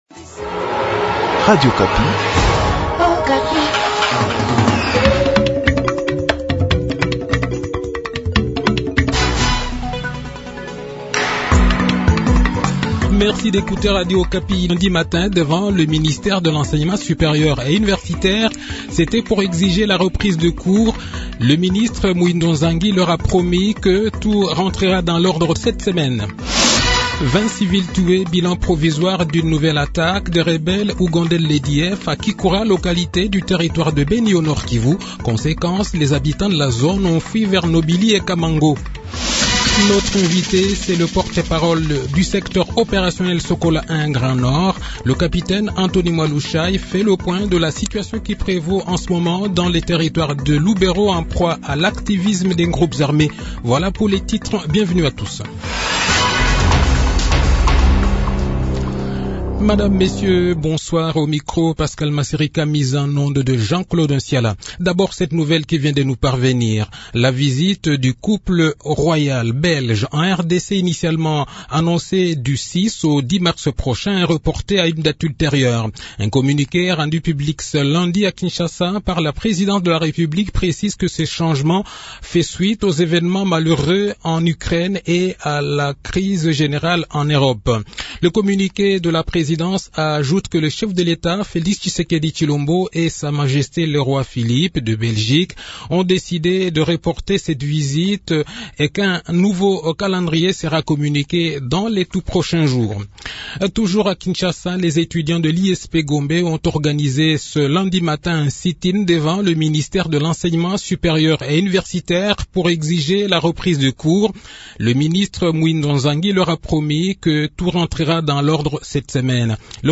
Le journal de 18 h, 28 février 2022